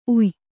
♪ uy